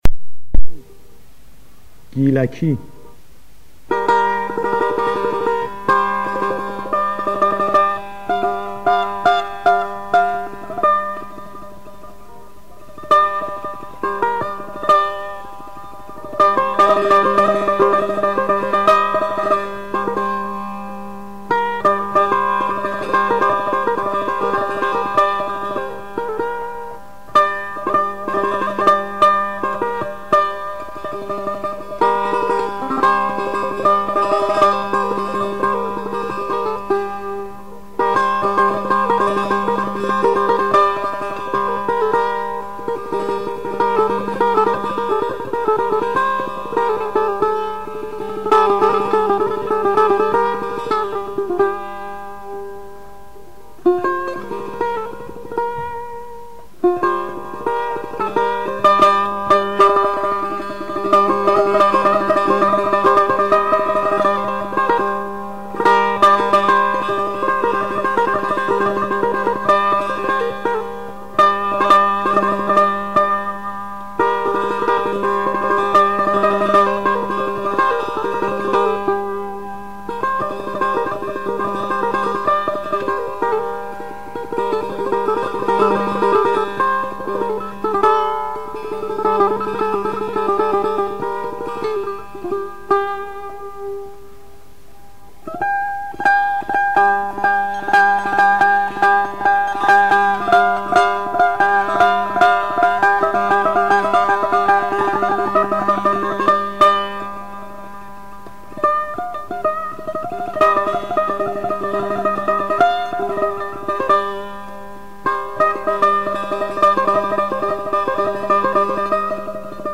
آواز دشتی ردیف میرزا عبدالله سه تار
گیلکی، آواز دشتی